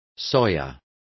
Complete with pronunciation of the translation of soya.